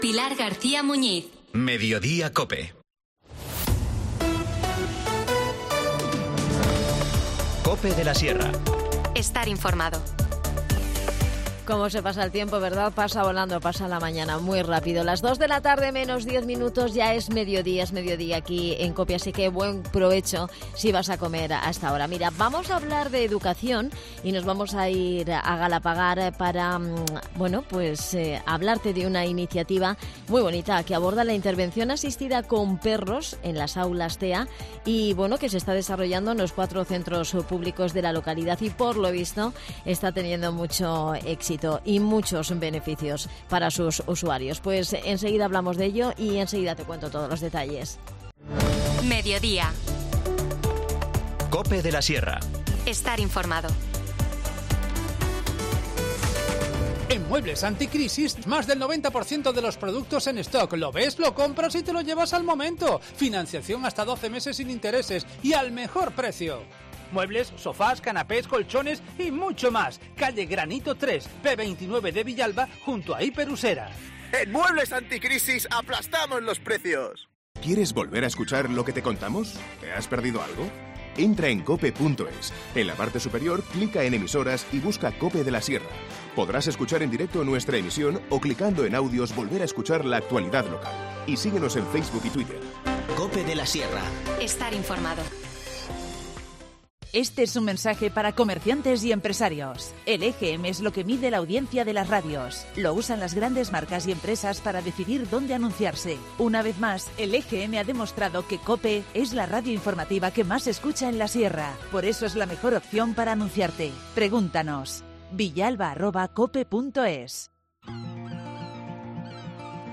Nos lo cuenta Jose María Escudero, concejal de Inclusión y Accesibilidad.